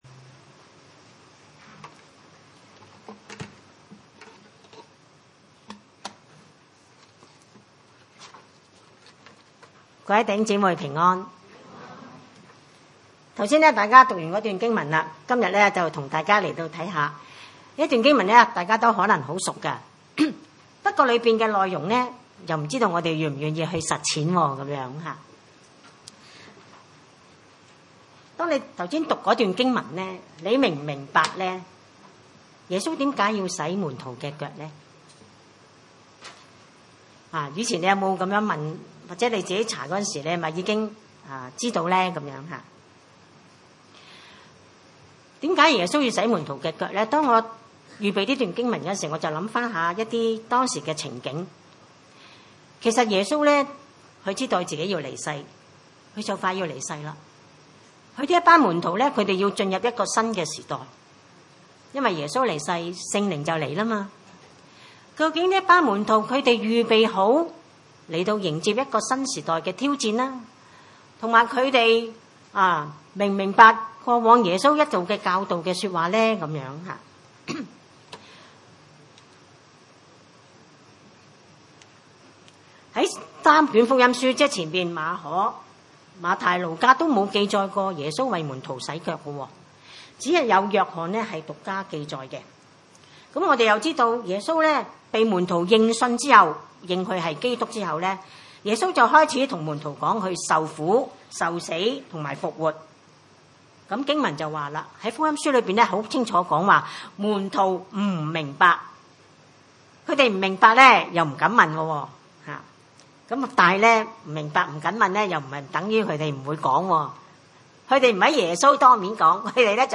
經文: 約翰福音13 : 1-17 崇拜類別: 主日午堂崇拜 1.逾越節以前，耶穌知道自己離世歸父的時候到了。